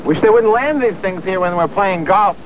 1 channel
playgolf.mp3